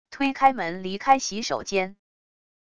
推开门离开洗手间wav音频